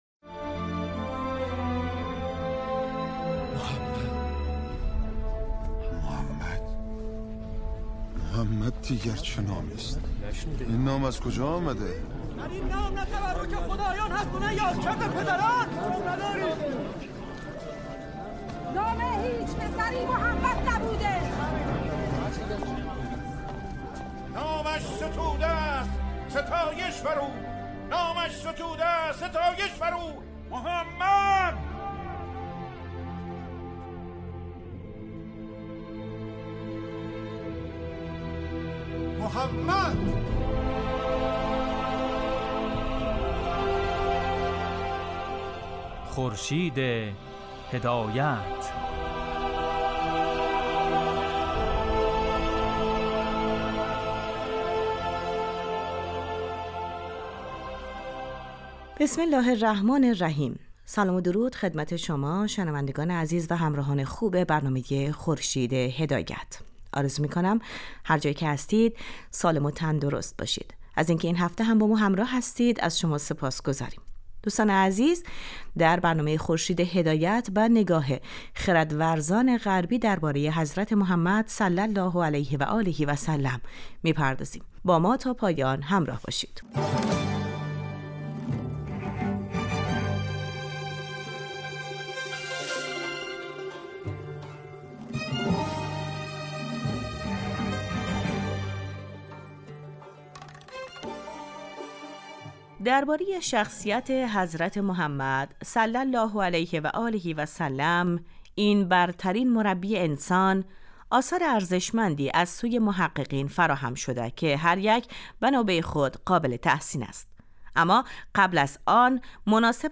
این برنامه در روزهای شنبه از رادیو دری پخش میشود و در آن به نگاه خردورزان غربی به سیمای پیامبر اسلام «ص» می پردازیم.